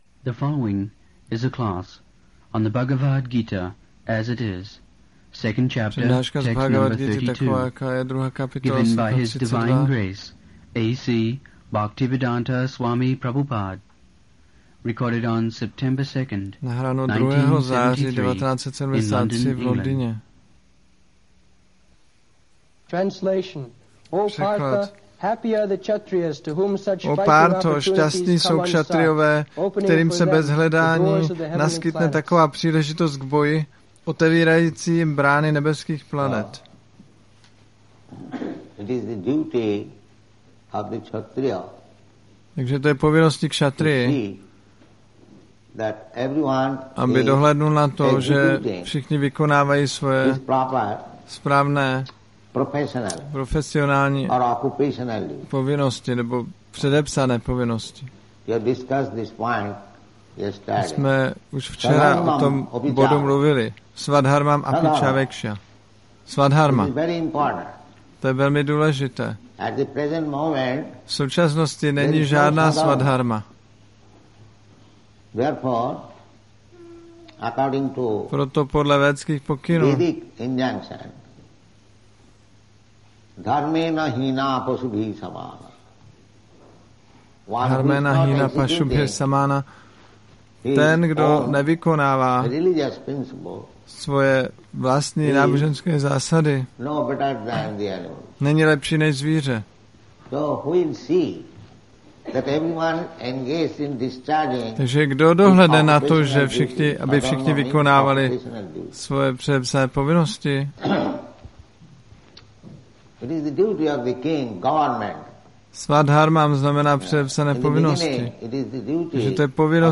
1973-09-02-ACPP Šríla Prabhupáda – Přednáška BG-2.32 London